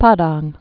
(pädäng, pä-däng)